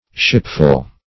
Meaning of shipful. shipful synonyms, pronunciation, spelling and more from Free Dictionary.